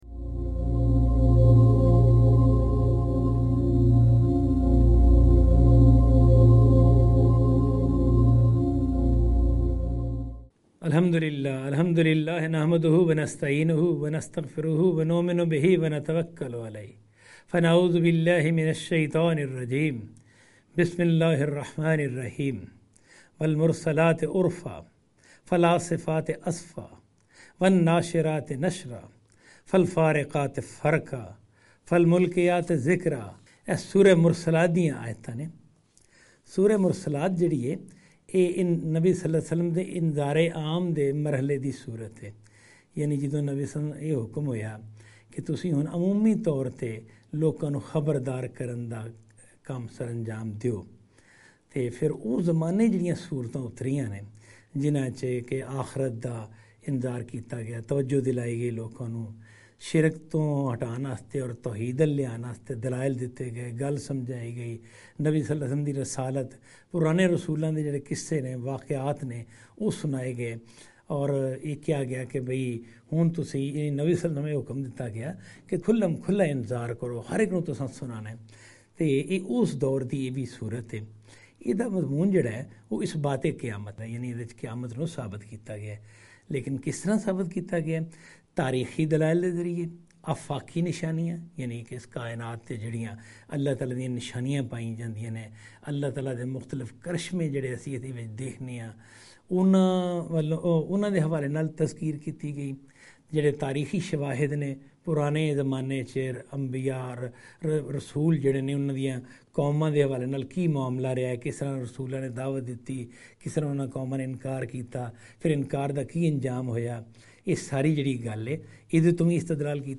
Qur'anic Surah Lecture series in Punjabi